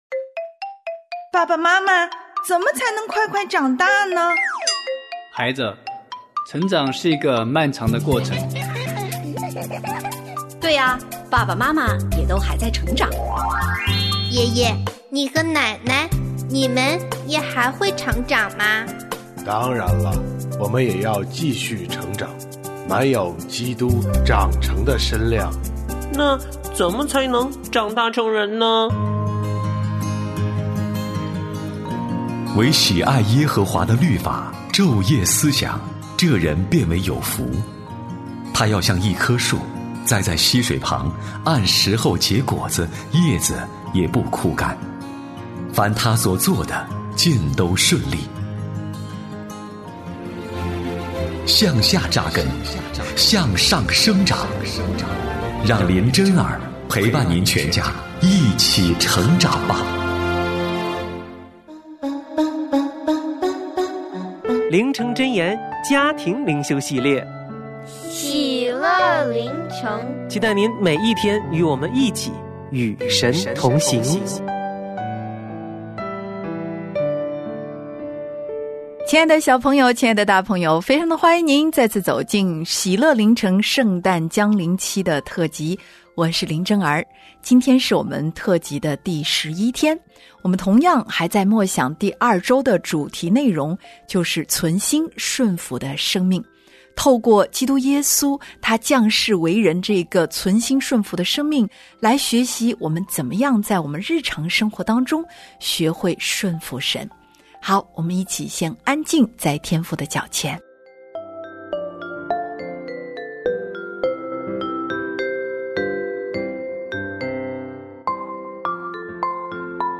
我家剧场：圣经广播剧（144）乃缦患大麻风；上帝借以利沙治好乃缦